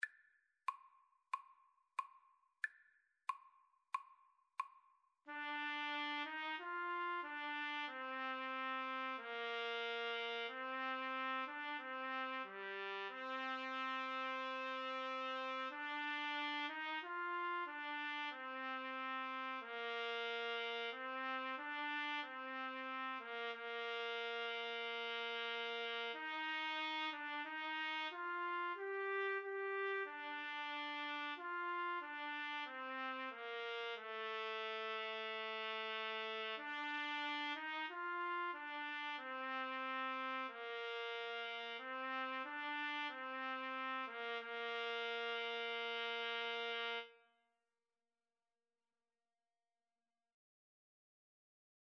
Andante = c. 92
Classical (View more Classical Trumpet-Trombone Duet Music)